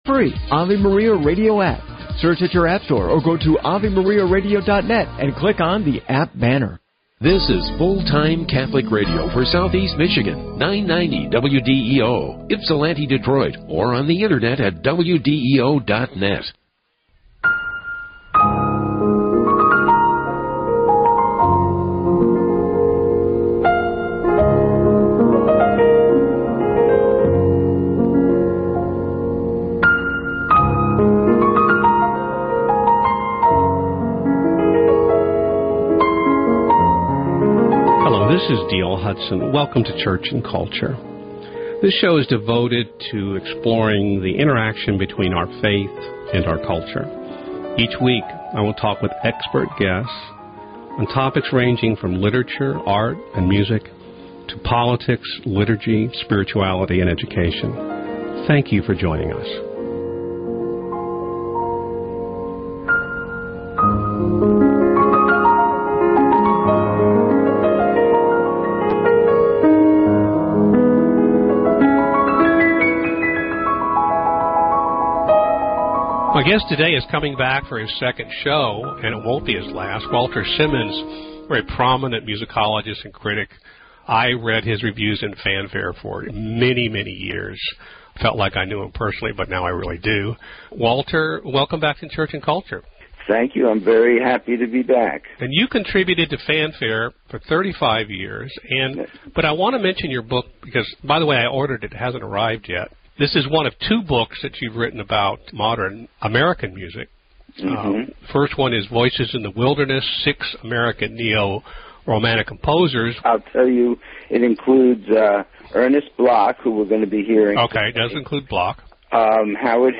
talks about and plays recordings of three Jewish composers: Ernst Bloch, Leonard Bernstein, and Arnold Rosner